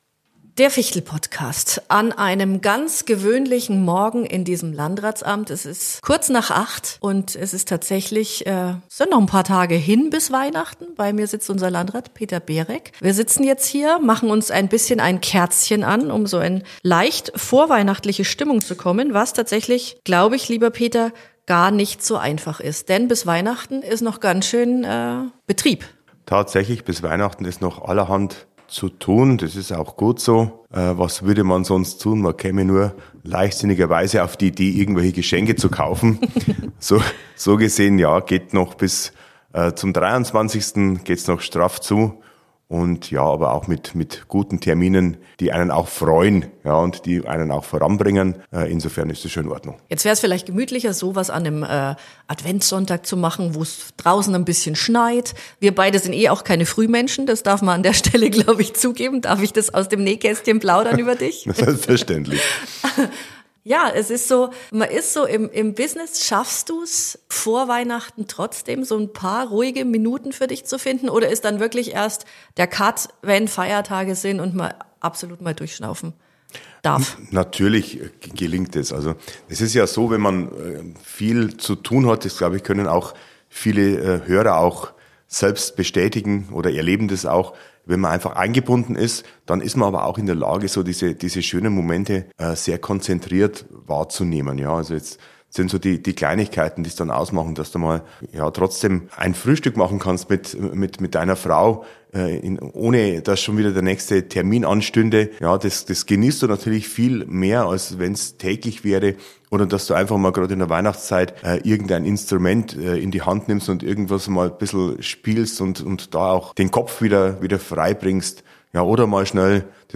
Landrat Peter Berek nimmt uns mit in seine ganz persönlichen Weihnachtserinnerungen. Er erzählt, welche kleinen Rituale für ihn dazugehören, wie er zum Schenken steht – und ob im Hause Berek wirklich bis zur letzten Minute gearbeitet wird. Ein ehrliches, warmes Gespräch über Traditionen, Erwartungen und die besondere Ruhe zwischen den Jahren.